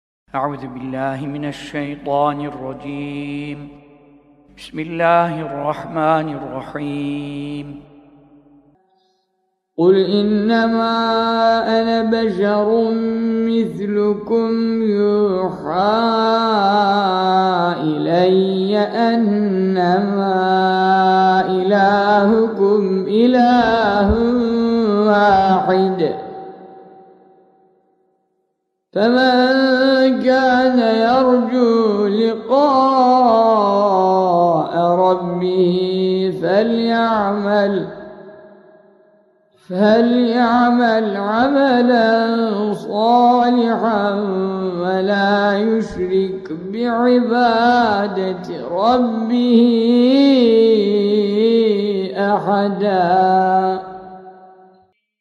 Kehf Suresi Son Ayetinin Kıraati